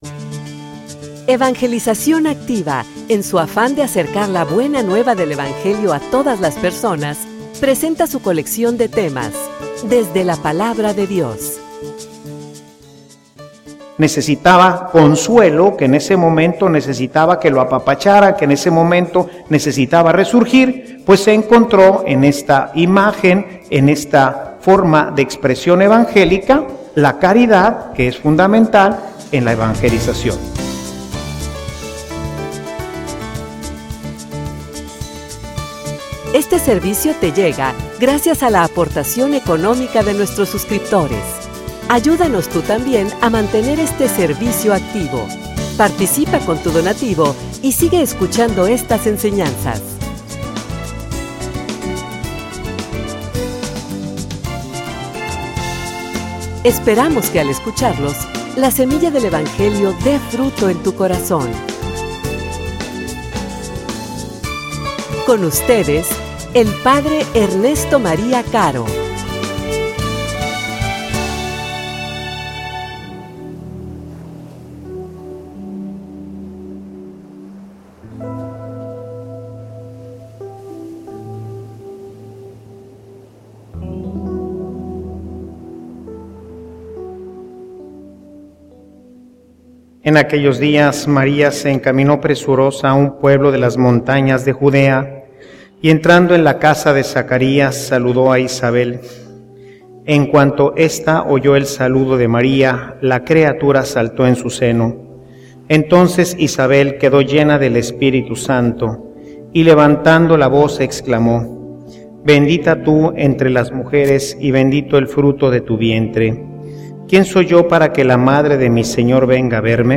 homilia_El_evangelio_de_la_tilma.mp3